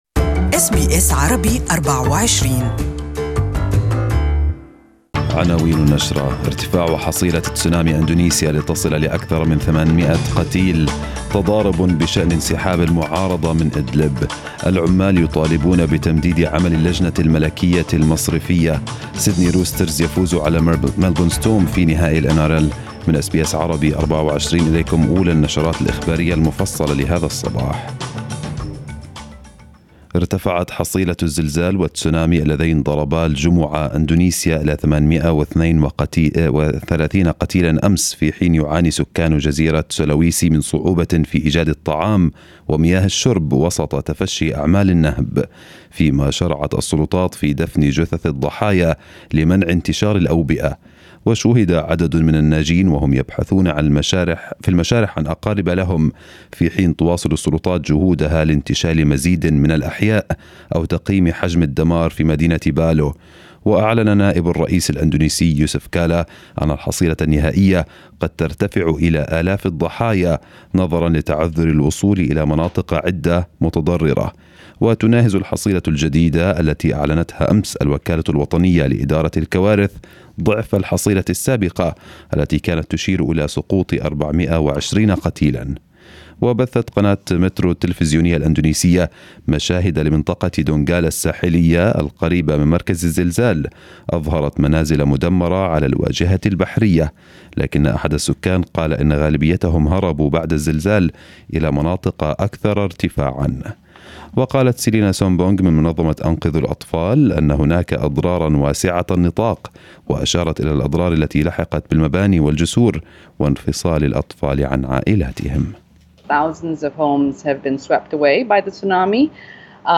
First news bulletin in the day